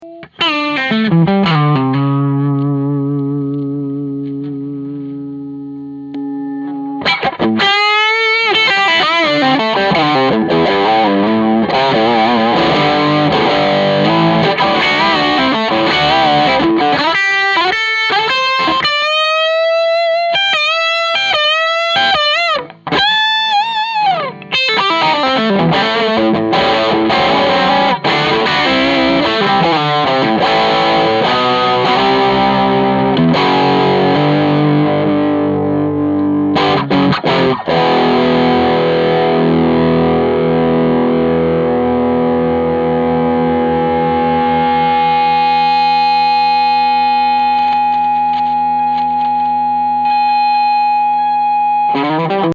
8. Gibson 335 OD PAB Overtones
ODS_335_OD_PAB_Overtones.mp3